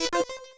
select.wav